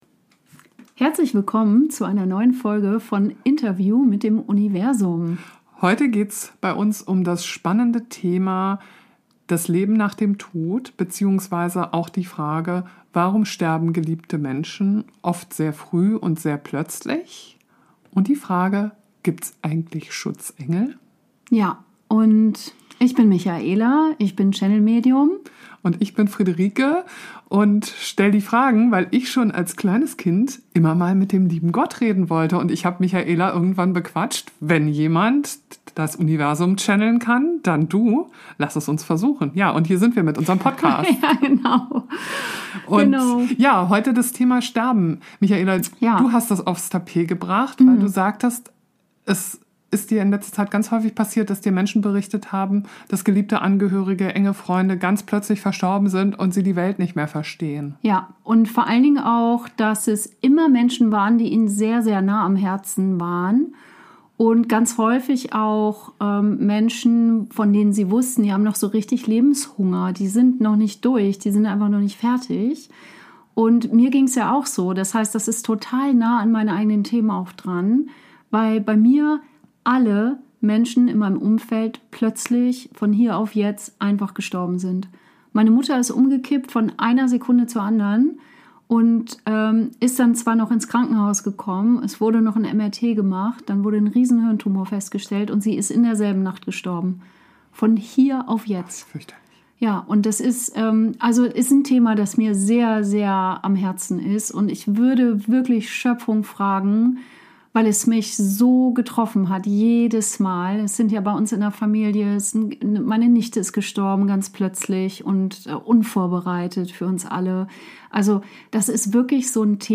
Gibt es ein Leben nach dem Tod? ~ Interview mit dem Universum Podcast